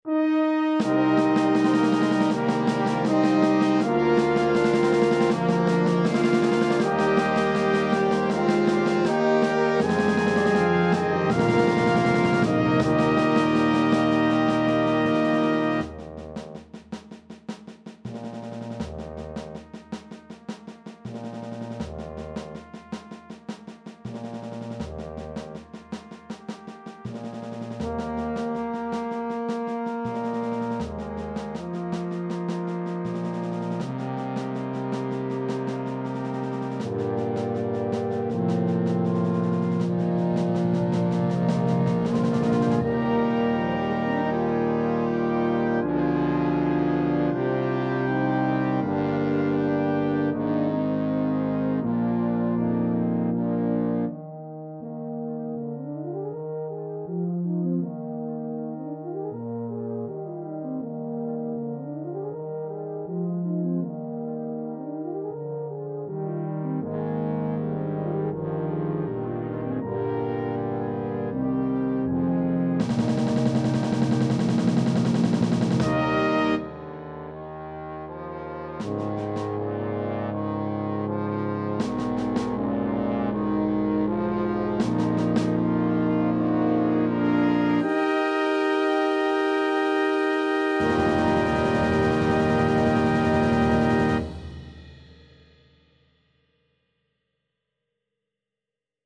(Fanfare)
cornet and trombone
this vibrant arrangement is based on the familiar carol